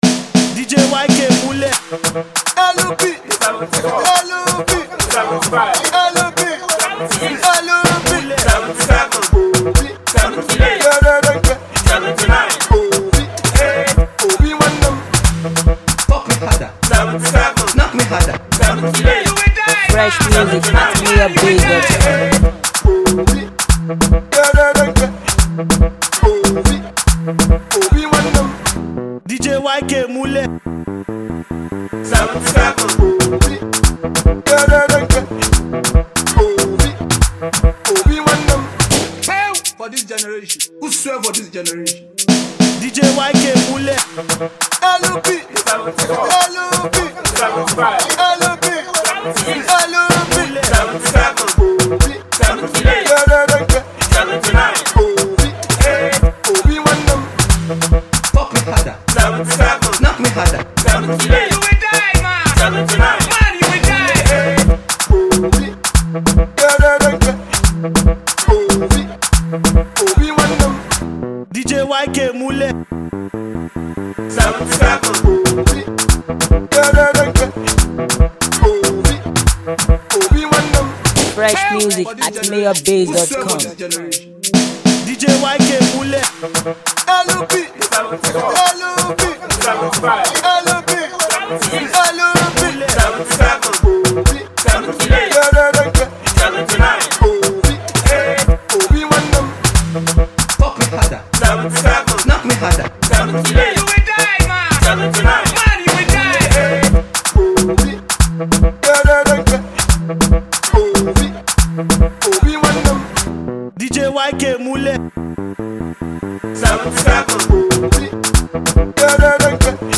street Beat